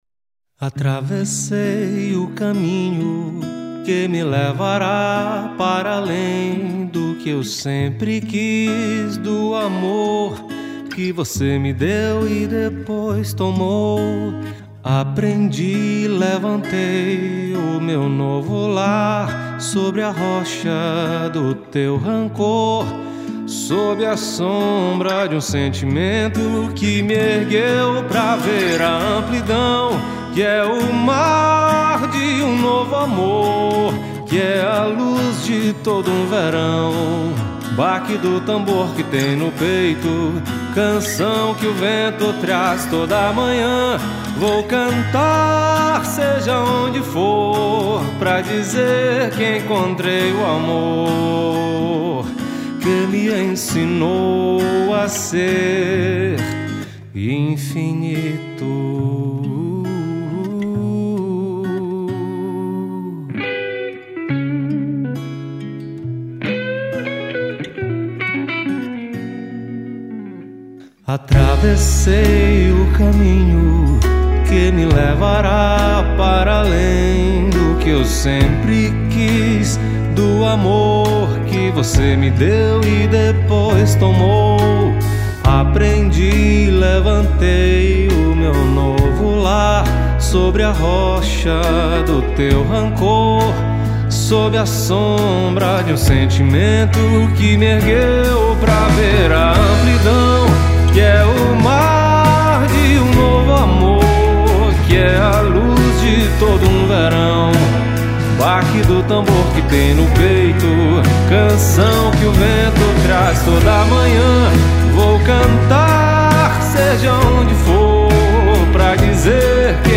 1274   03:50:00   Faixa:     Rock Nacional